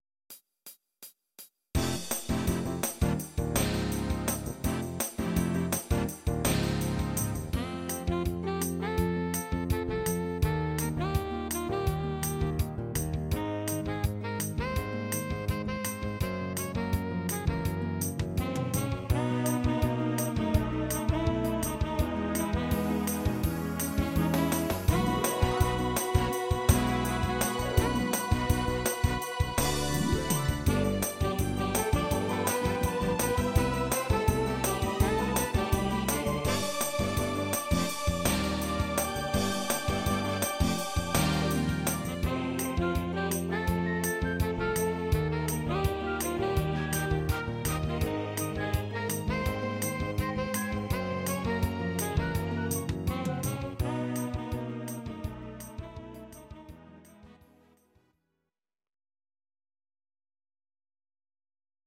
Audio Recordings based on Midi-files
Pop, Oldies, 1960s